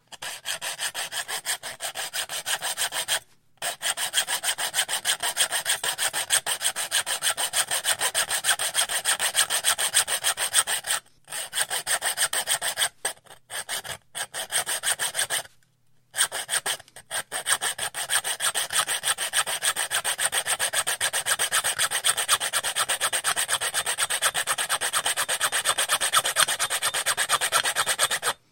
На этой странице собраны звуки ножовки в разных вариациях: от плавных движений по дереву до резких рывков при работе с металлом.
Звук скрипящего металла при распиливании решетки ножовкой